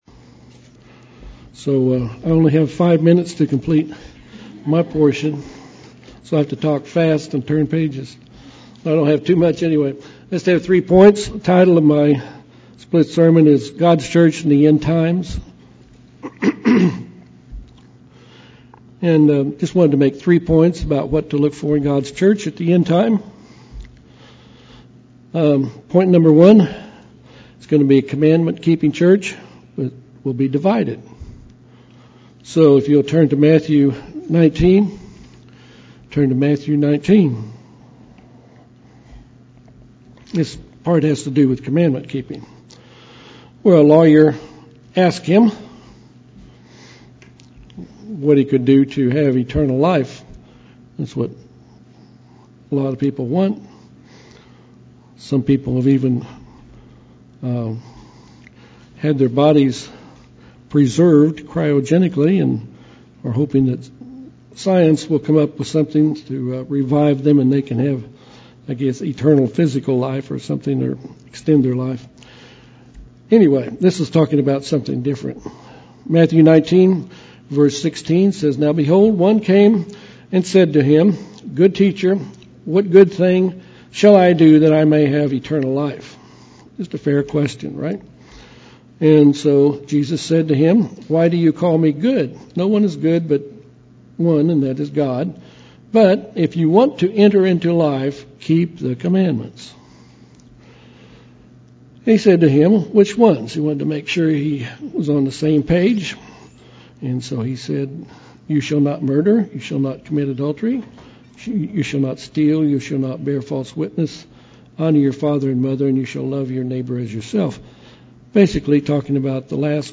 Some will be protected during the great tribulation and some won't UCG Sermon Studying the bible?